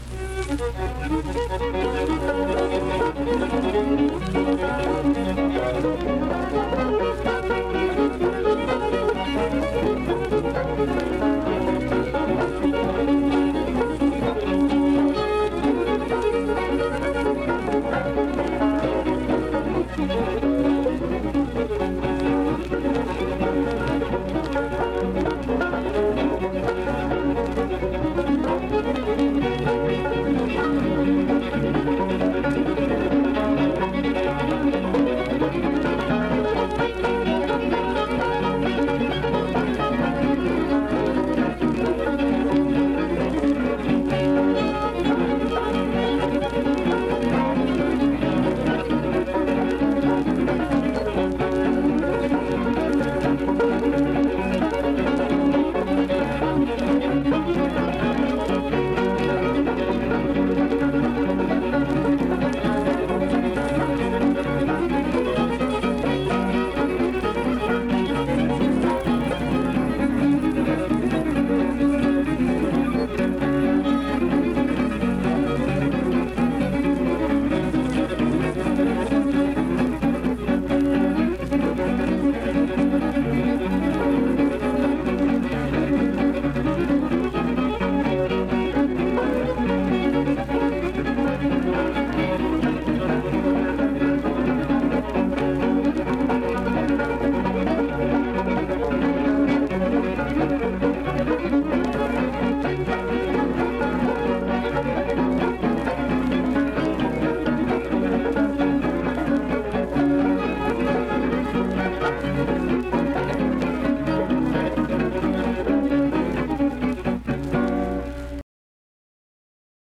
Instrumental performance with fiddle, banjo, and guitar.
Instrumental Music
Banjo, Fiddle, Guitar
Vienna (W. Va.), Wood County (W. Va.)